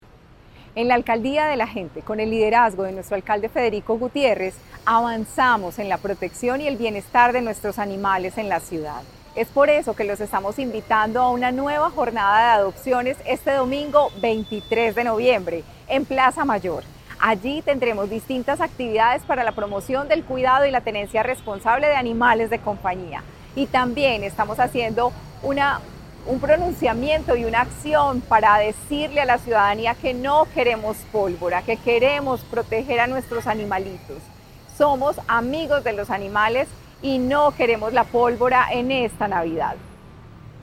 Declaraciones secretaria de Medio Ambiente, Marcela Ruiz
Declaraciones-secretaria-de-Medio-Ambiente-Marcela-Ruiz-3.mp3